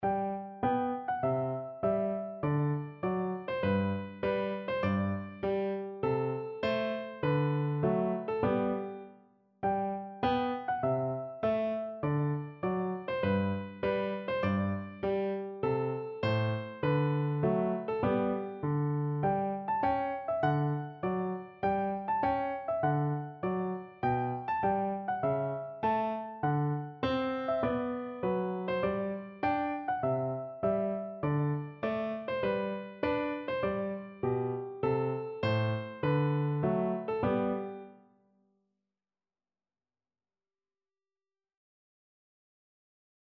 No parts available for this pieces as it is for solo piano.
G major (Sounding Pitch) (View more G major Music for Piano )
4/4 (View more 4/4 Music)
Moderato
Instrument:
Traditional (View more Traditional Piano Music)